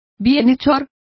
Complete with pronunciation of the translation of benefactor.